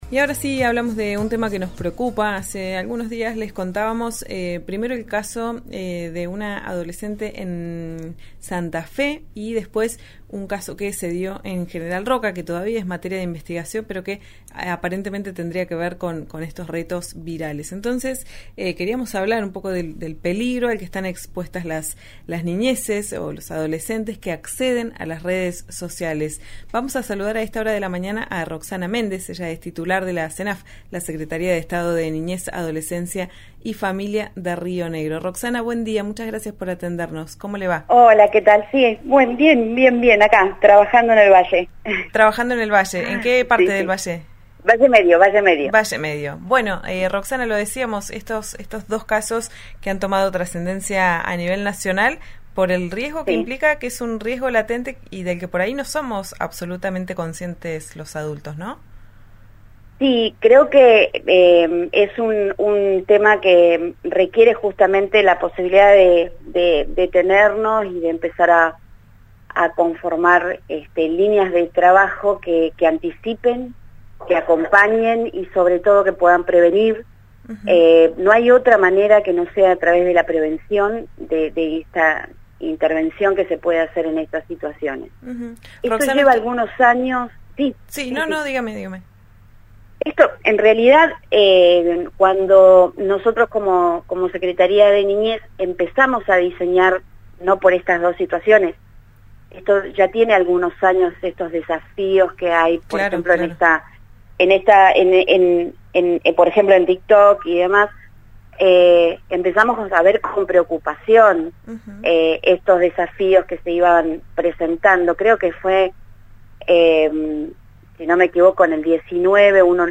Roxana Méndez, titular de la Secretaria de Niñez y familia de Río Negro, habló con RÍO NEGRO RADIO y anunció líneas de acción que implementarán en educación digital.